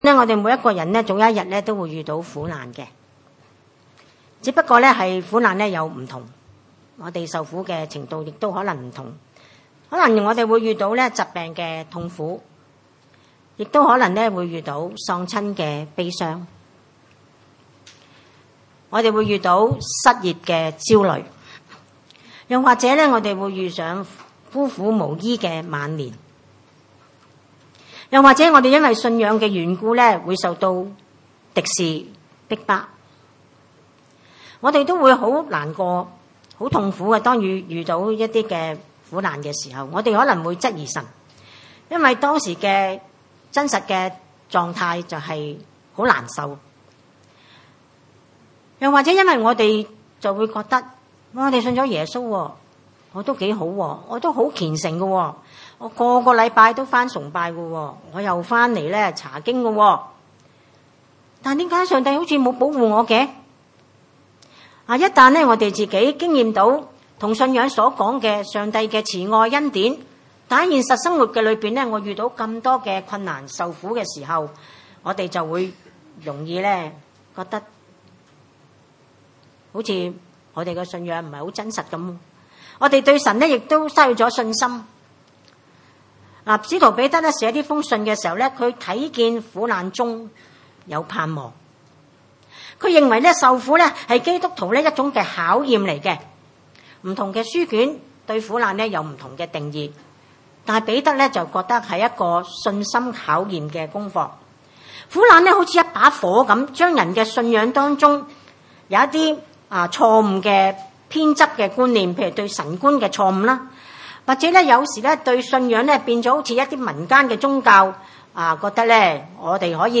19 崇拜類別: 主日午堂崇拜 12 親愛的弟兄啊，有火煉的試驗臨到你們，不要以為奇怪（似乎是遭遇非常的事）， 13 倒要歡喜；因為你們是與基督一同受苦，使你們在他榮耀顯現的時候，也可以歡喜快樂。